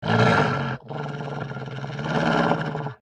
bdog_growl_1.ogg